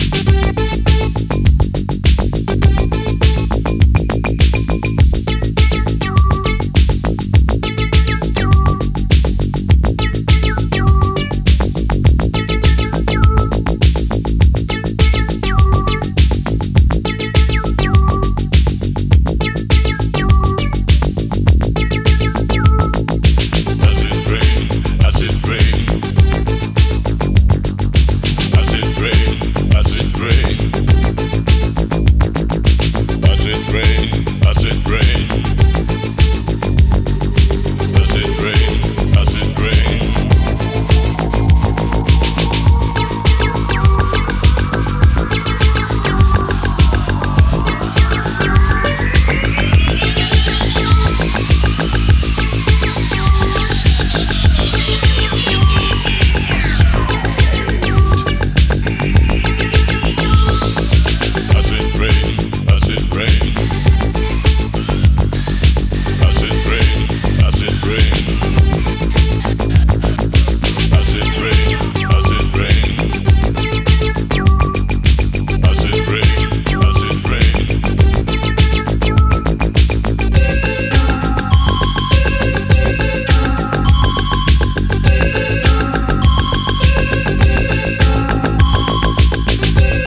Belgian New Beat
House Techno Wave